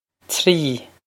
Trí tree
Pronunciation for how to say
This is an approximate phonetic pronunciation of the phrase.